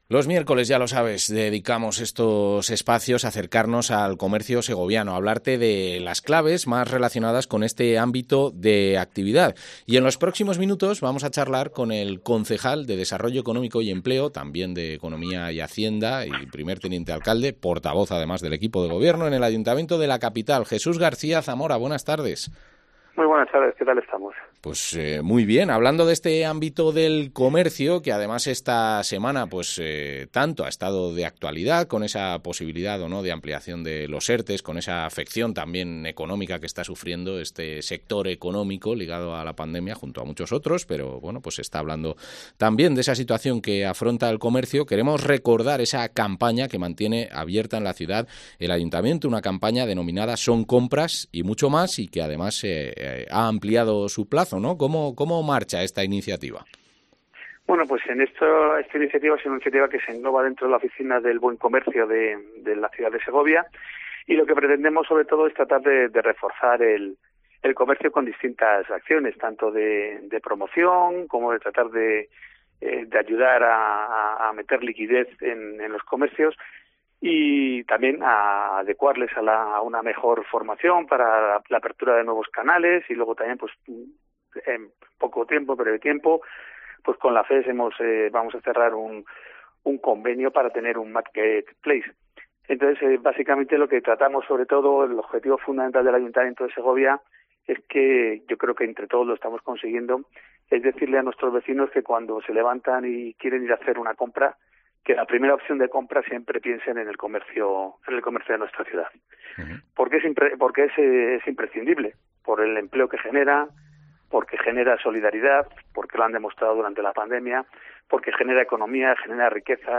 Entrevista al concejal de Desarrollo Económico y Empleo, Jesús García Zamora